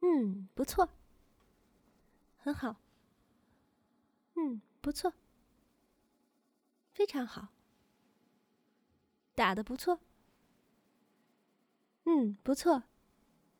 鼓掌1.wav
鼓掌1.wav 0:00.00 0:13.61 鼓掌1.wav WAV · 1.1 MB · 單聲道 (1ch) 下载文件 本站所有音效均采用 CC0 授权 ，可免费用于商业与个人项目，无需署名。